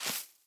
sounds / block / moss / step2.ogg